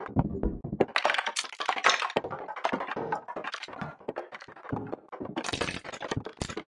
Rattle Sound Collection » F11B
描述：A line of noisy and grainy sound elements taken from wooden sound sources, moving up and down.
标签： grains noise rattle wood
声道立体声